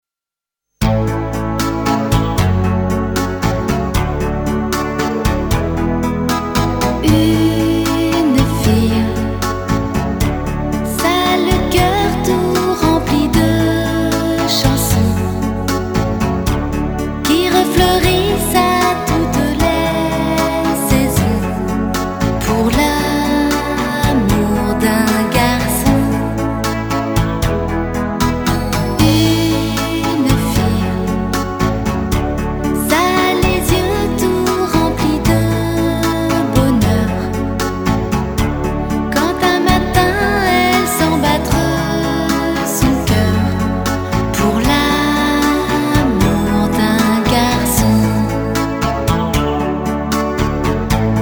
• Качество: 320, Stereo
OST
Красивая песня из 90х.